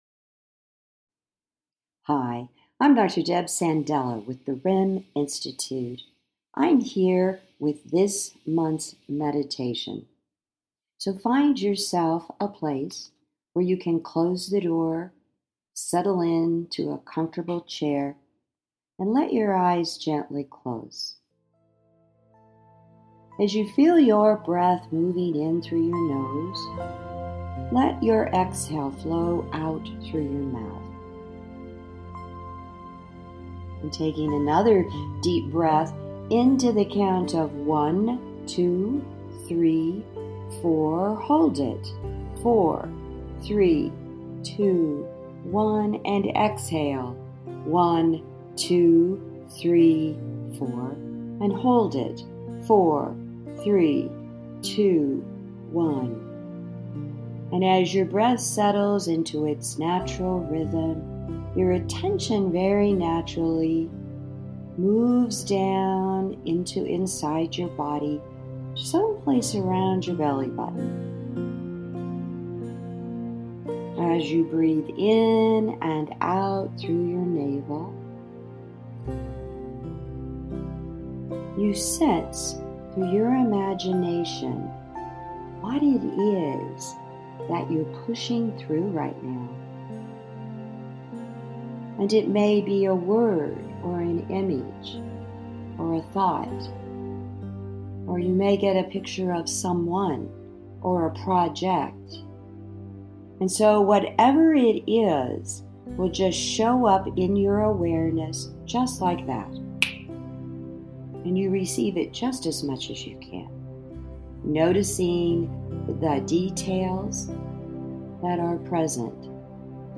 February-15-Meditation.mp3